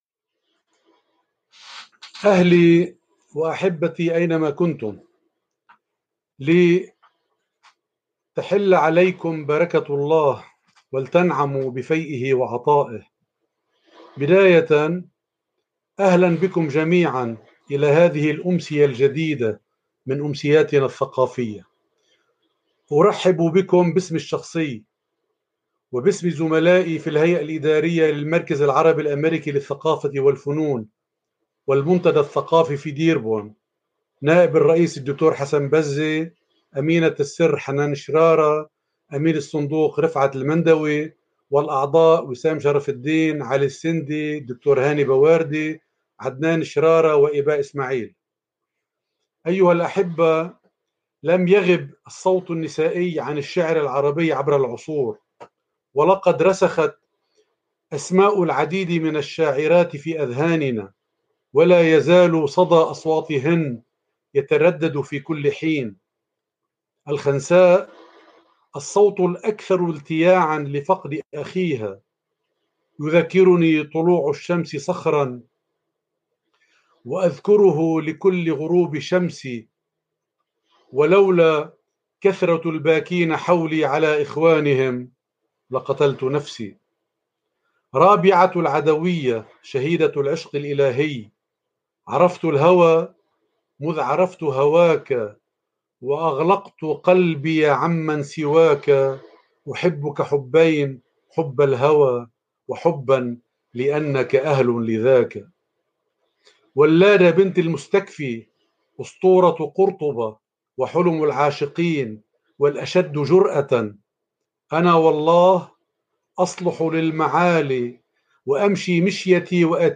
four-poetess-from-lebanon.mp3